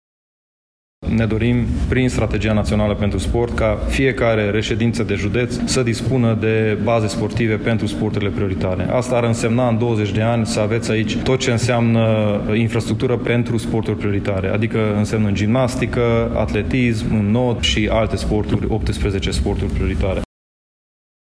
Prezent la Brașov unde a asistat la Finala Cupei României la Volei Masculin, ministrul Sportului, Eduard Novak, a punctat elementele de strategie în dezvoltarea sportului național marcând rolul pe care îl va avea Brașovul.
Brașovul este inclus de asemenea în strategia națională ce presupune printre altele creearea unor ramuri prioritare în următorii 20 de ani în sportul românesc. Ministrul Eduard Novak: